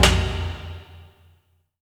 A#3 DRUMS0FL.wav